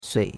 sui3.mp3